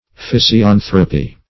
Meaning of physianthropy. physianthropy synonyms, pronunciation, spelling and more from Free Dictionary.
Search Result for " physianthropy" : The Collaborative International Dictionary of English v.0.48: Physianthropy \Phys`i*an"thro*py\, n. [Gr. fy`sis nature + 'a`nqrwpos man.]